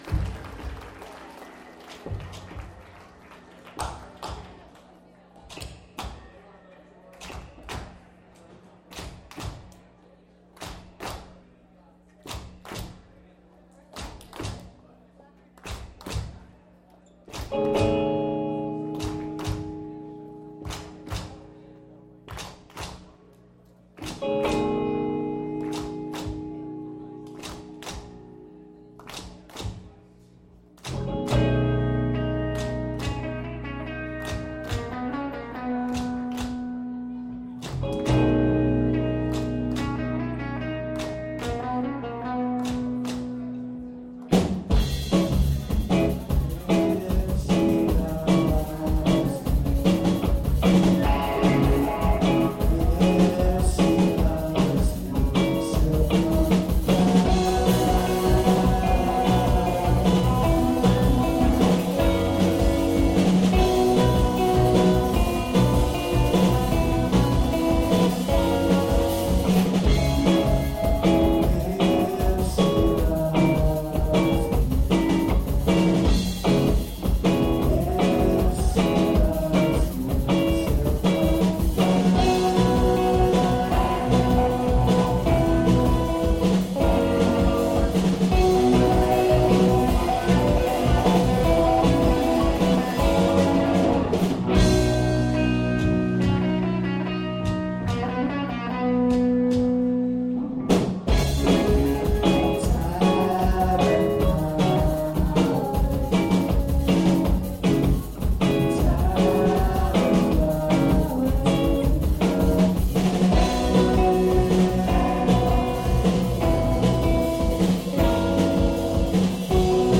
64kbit Mono MP3s
Audience recording